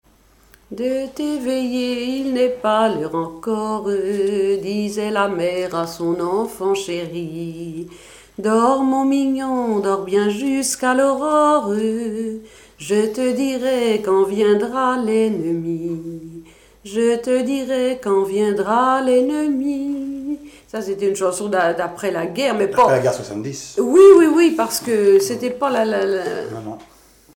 Genre strophique
Chansons et commentaires
Pièce musicale inédite